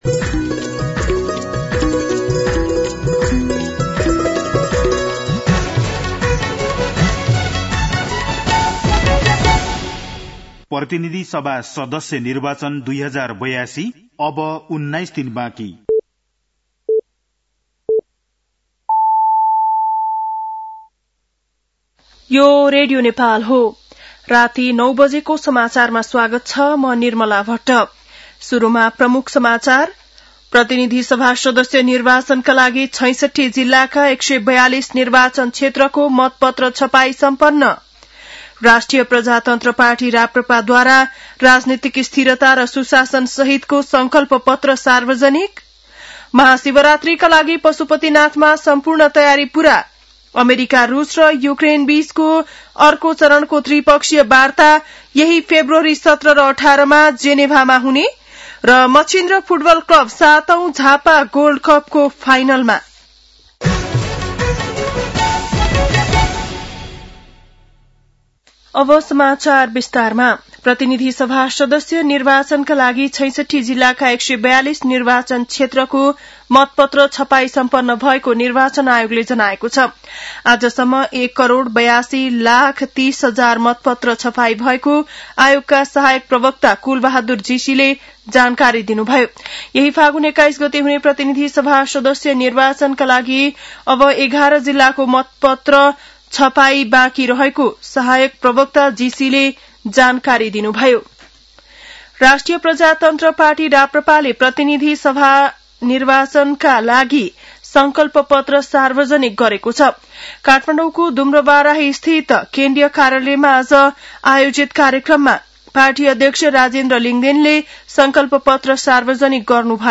बेलुकी ९ बजेको नेपाली समाचार : २ फागुन , २०८२
9.-pm-nepali-news-1-1.mp3